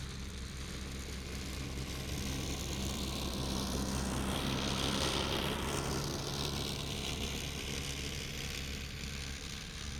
Zero Emission Snowmobile Description Form (PDF)
Zero Emission Subjective Noise Event Audio File - Run 1 (WAV)